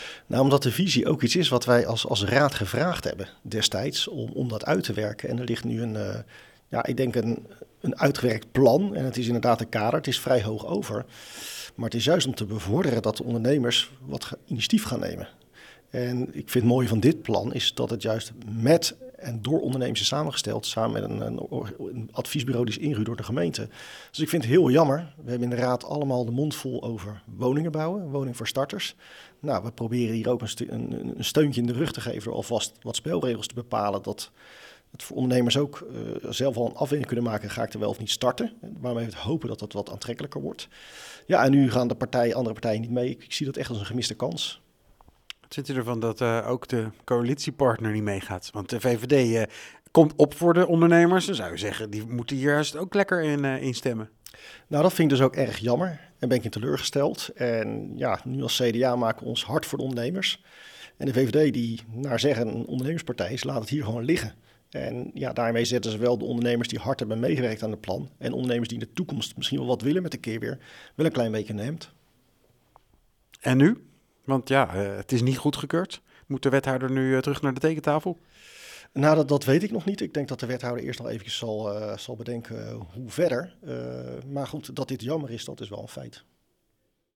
spreekt met Mathijs van der Hoeven, fractievoorzitter van het CDA over waarom het CDA als enige partij voor het voorstel stemde.